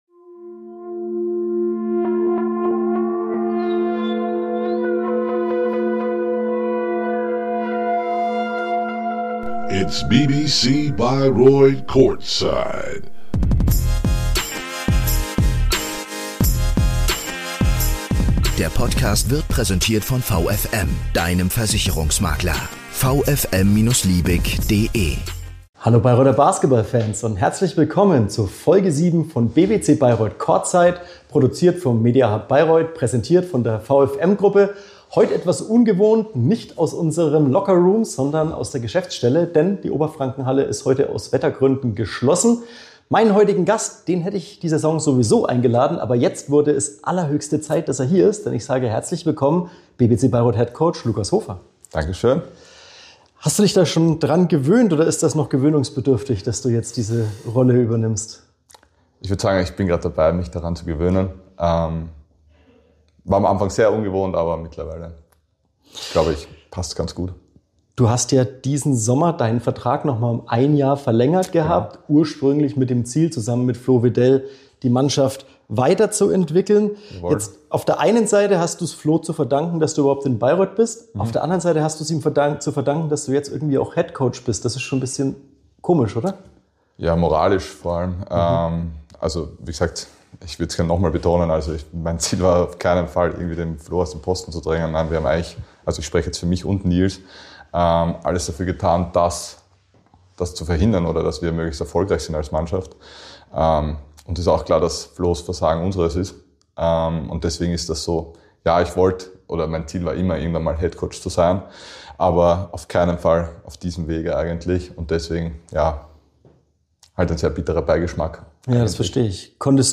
Diesmal ist bei BBC Bayreuth Courtside alles ein wenig anders: Statt Lockerroom-Atmosphäre gibt’s Folge 7 aus der Geschäftsstelle – die Oberfrankenhalle ist wetterbedingt zu.